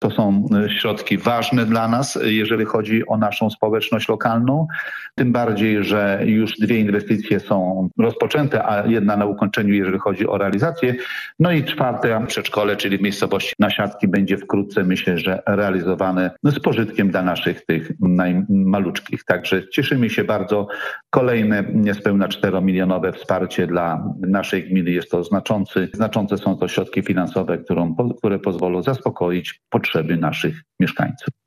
Na realizację tych zadań przekazano blisko 4 mln złotych – mówi wójt gminy Lelis, Stefan Prusik: